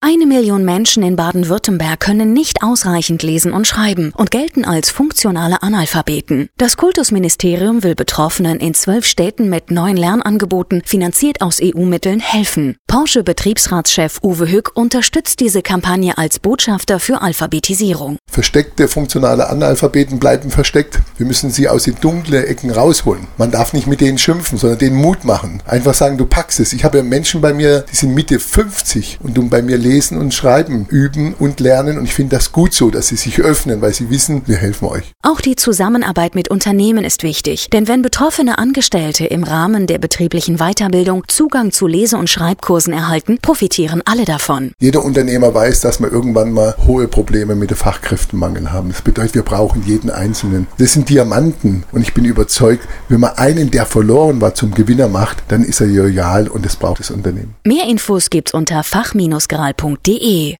Radiobeitrag mit Uwe Hück, Porsche AG